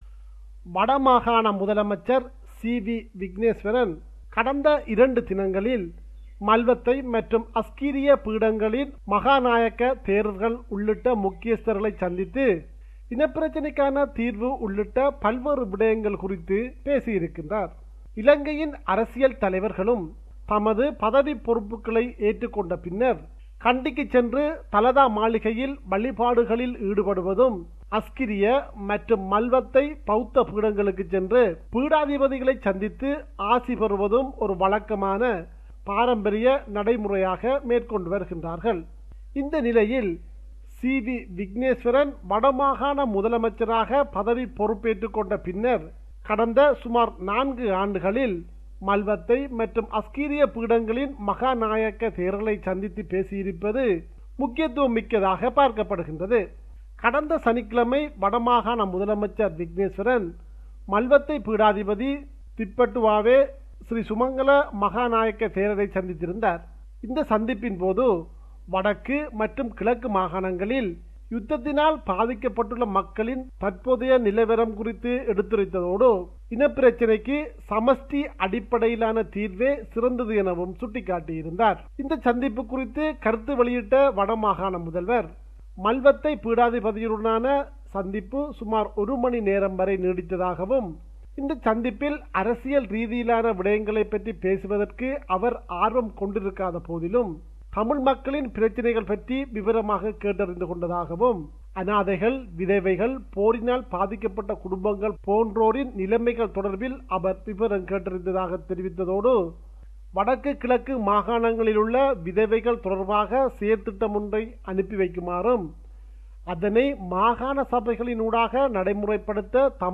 compiled a report focusing on major events in Sri Lanka.